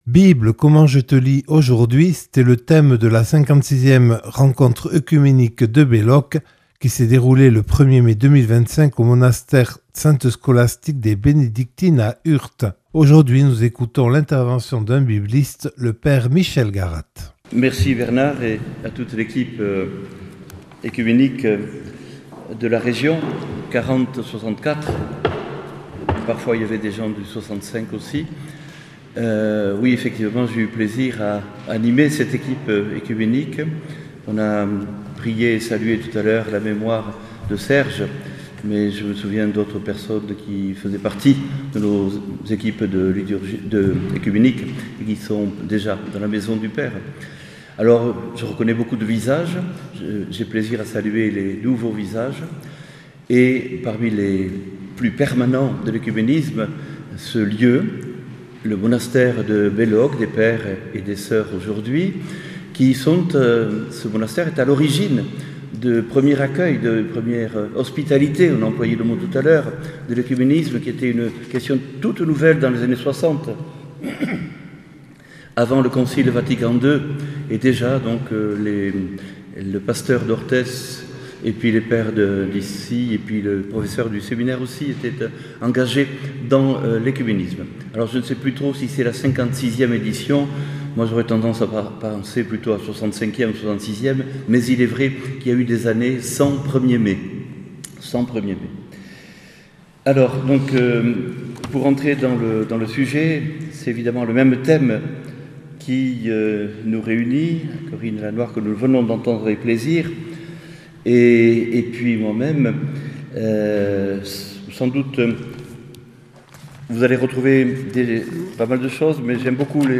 (Enregistré le 01/05/2025 au monastère Sainte-Scholastique de Belloc à Urt).